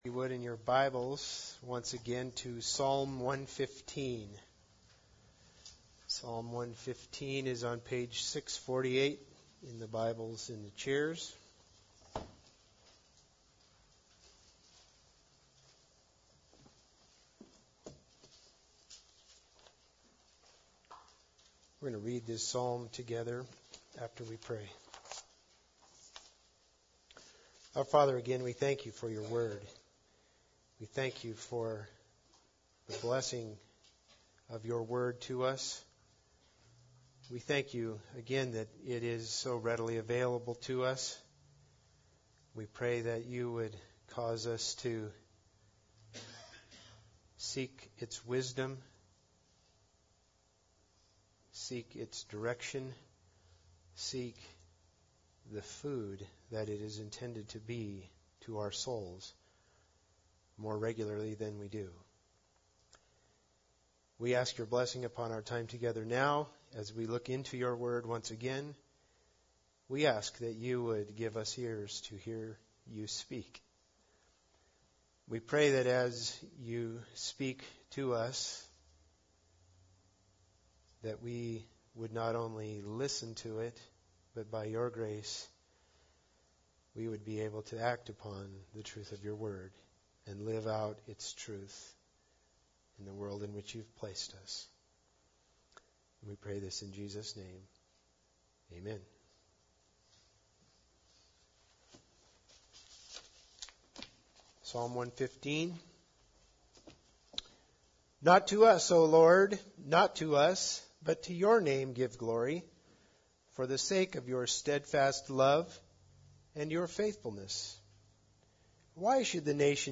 Psalms 115:9-18 Service Type: Special Service Bible Text